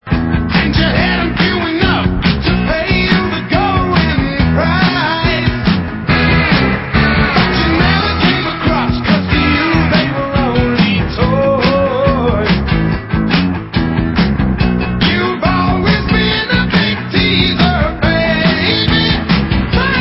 • styl: Glam